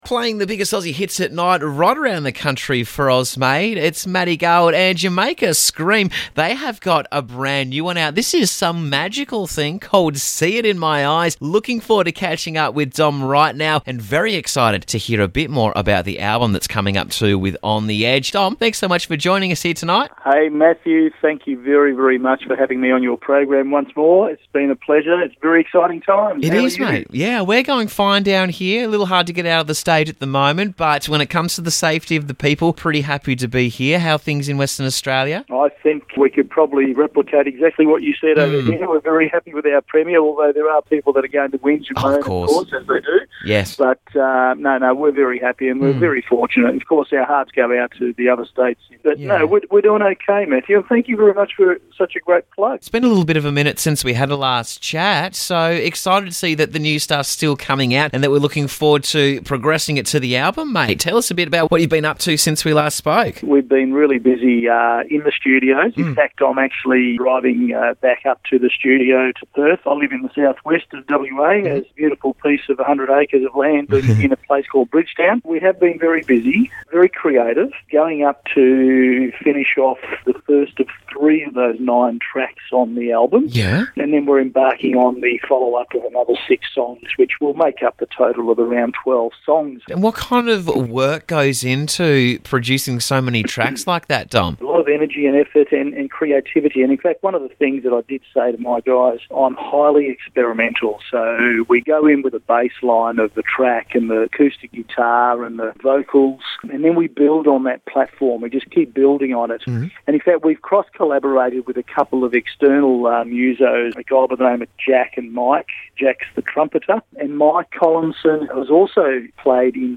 High energy Rock Band Jamaica Scream chat new single "See It In My Eyes"